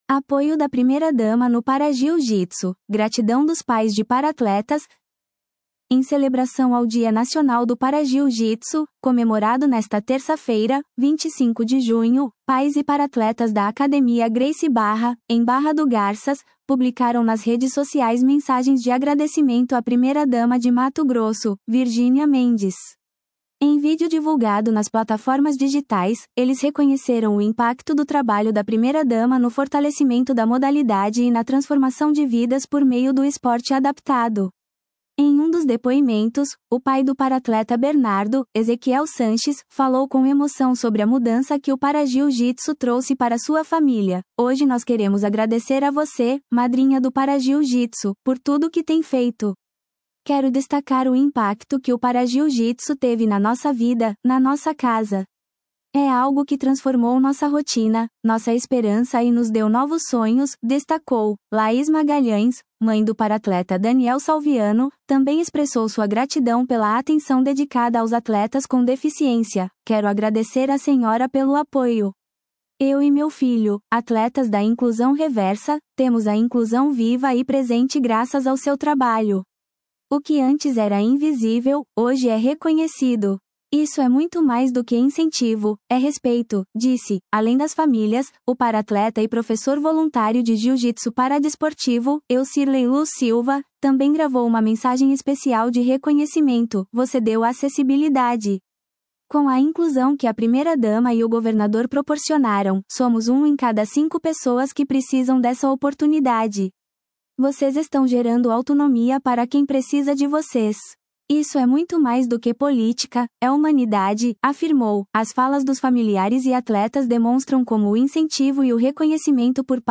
Em vídeo divulgado nas plataformas digitais, eles reconheceram o impacto do trabalho da primeira-dama no fortalecimento da modalidade e na transformação de vidas por meio do esporte adaptado.